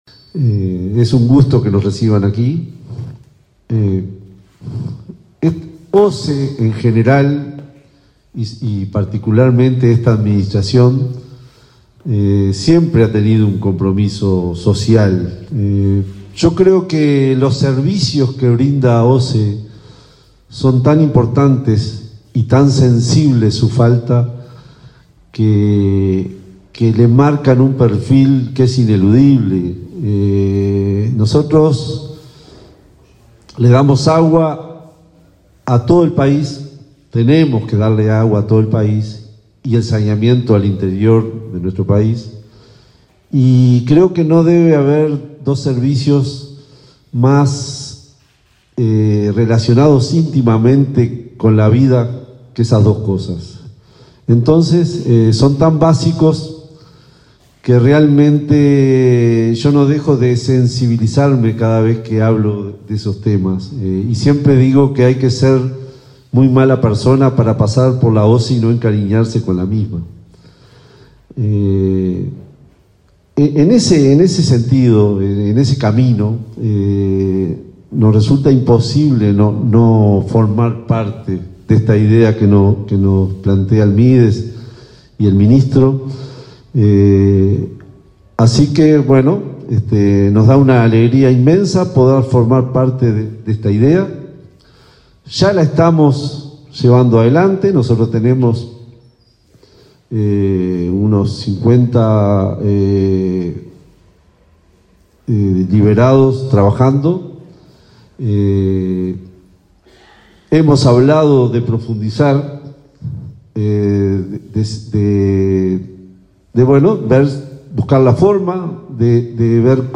Palabras de autoridades en firma de convenio entre OSE y Dirección del Liberado
Este viernes 27, el Ministerio de Desarrollo Social (Mides), a través de la Dirección Nacional del Liberado (Dinali), firmó un convenio con OSE, para otorgar pasantías de trabajo a personas que han egresado del sistema carcelario. El presidente de la empresa estatal, Raúl Montero, y el titular de la secretaría de Estado, Martín Lema, señalaron la importancia del acuerdo.